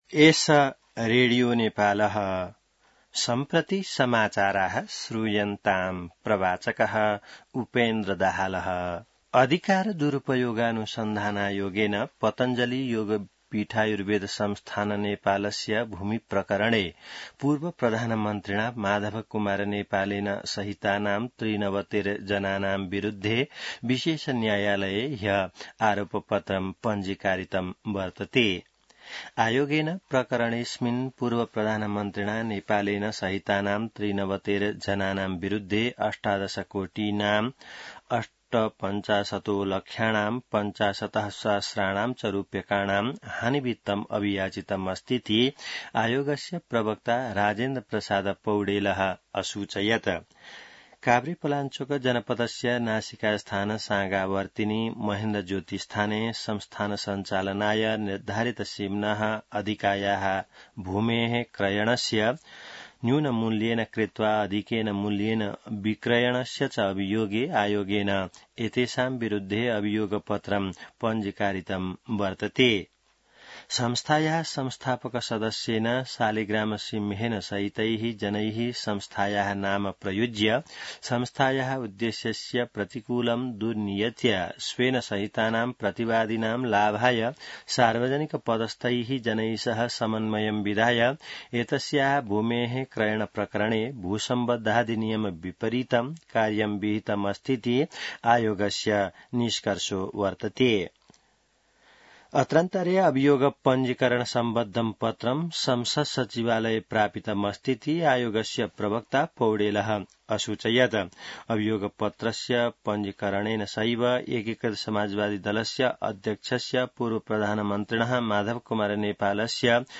संस्कृत समाचार : २३ जेठ , २०८२